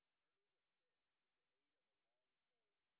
sp09_white_snr0.wav